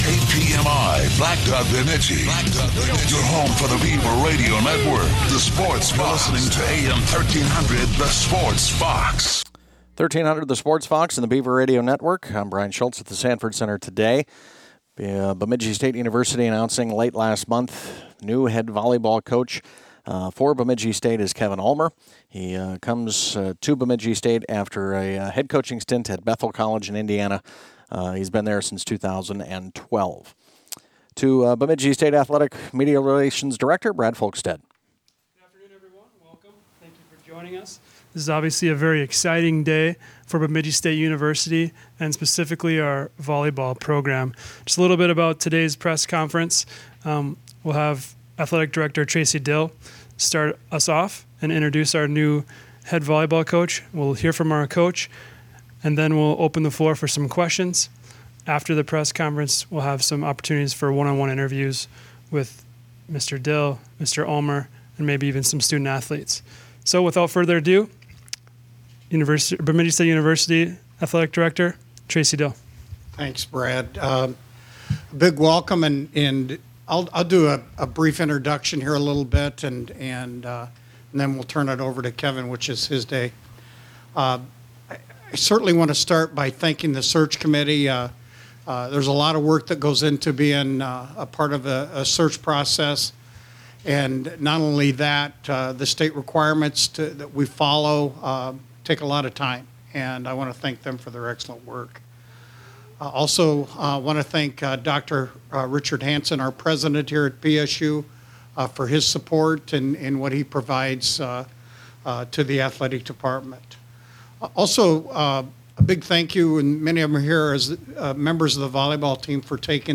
The Beaver Radio Network was proud to bring you the press conference LIVE today.